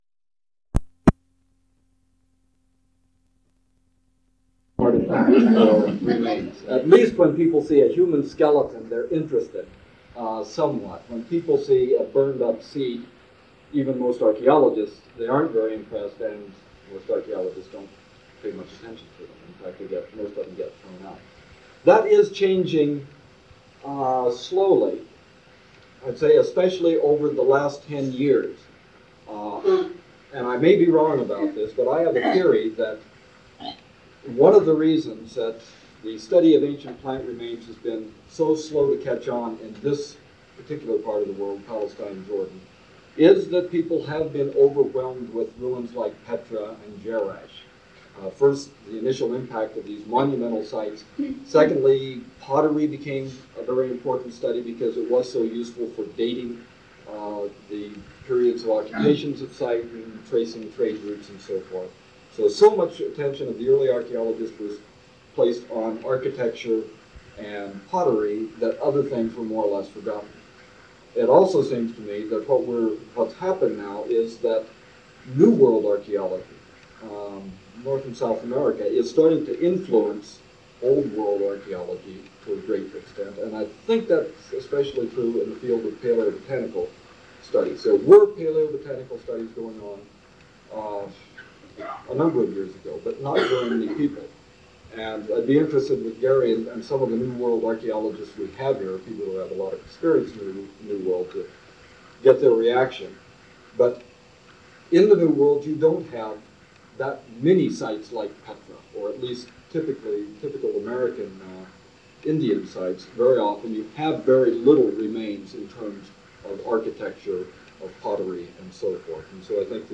Archaeological Methodology - Lecture 16: Introduction to Paleoethnobotany